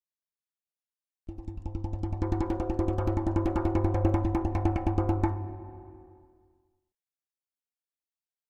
Drums Percussion Danger - Slower Drumming On A Thin Metal Percussion 1